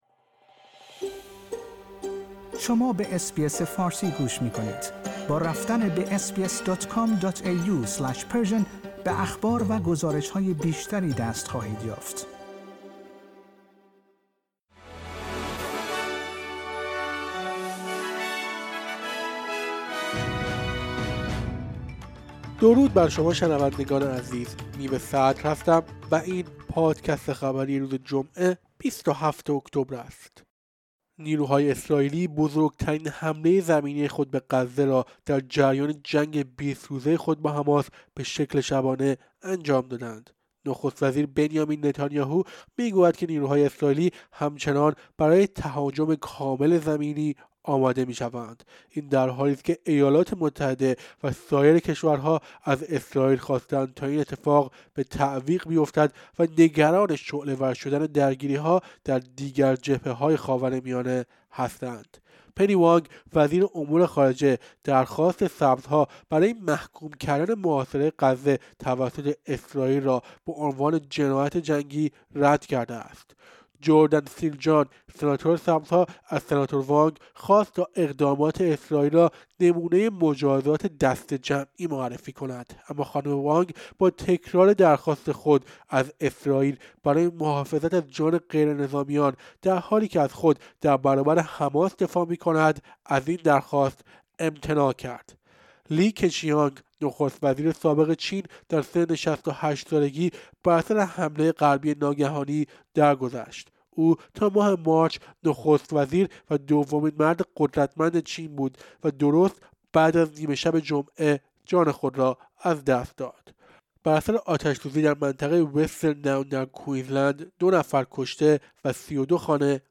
در این پادکست خبری مهمترین اخبار استرالیا و جهان در روز جمعه ۲۷ اکتبر، ۲۰۲۳ ارائه شده است.